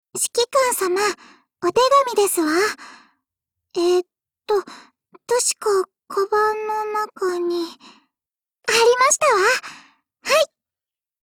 碧蓝航线:小光辉语音